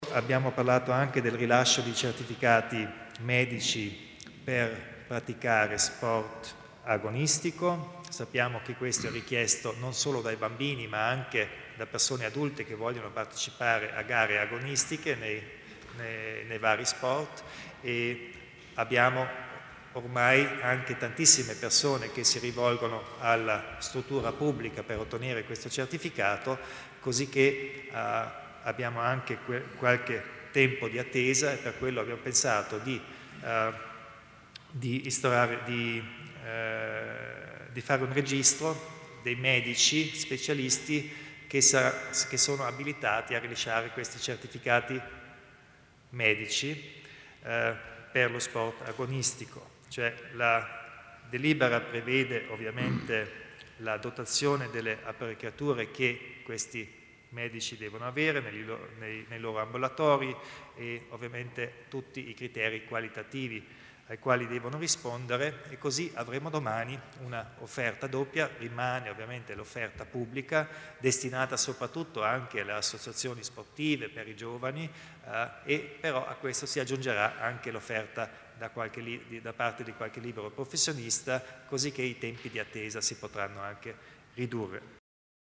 Il Presidente Kompatscher illustra le novità in tema di medicina dello sport